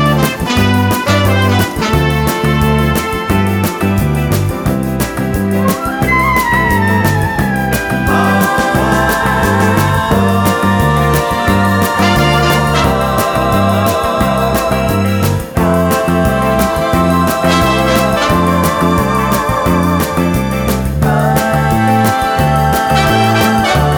No Backing Vocals Crooners 3:16 Buy £1.50